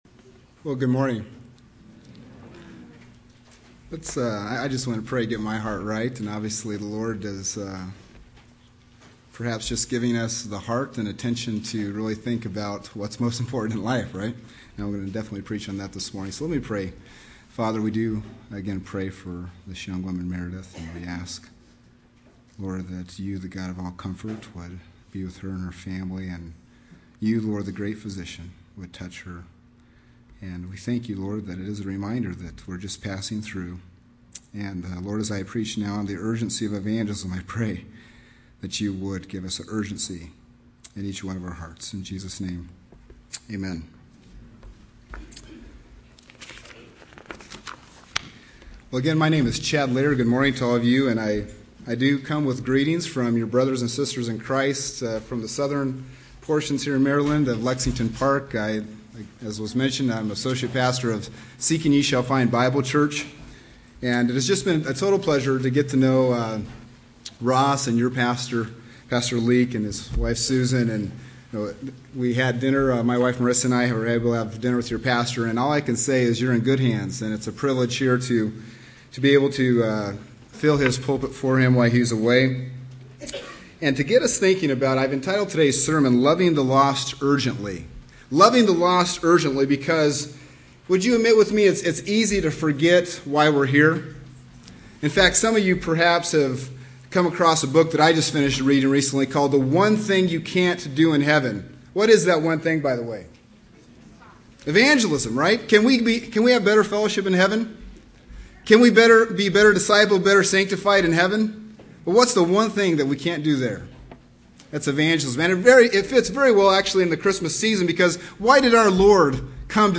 Category: Sunday Service